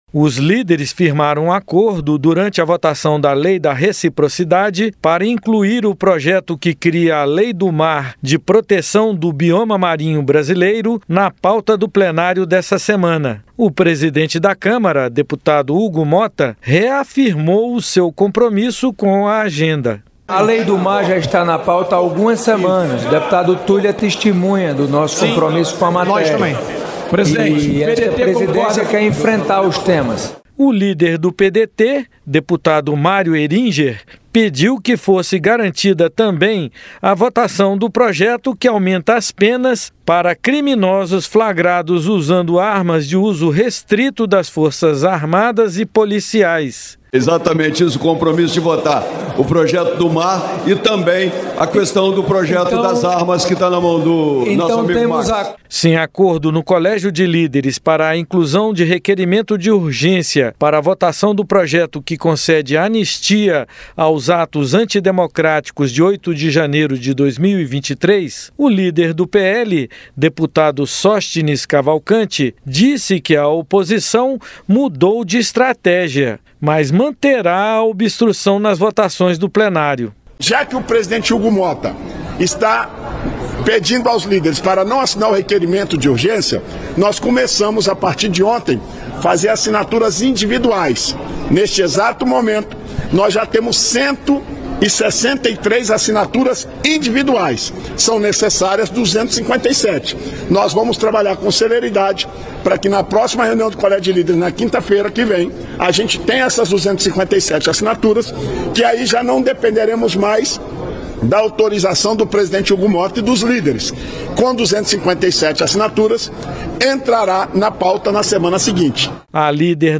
Deputados devem votar Lei do Mar em meio a obstrução do PL por anistia a acusados do 8 de janeiro - Radioagência - Portal da Câmara dos Deputados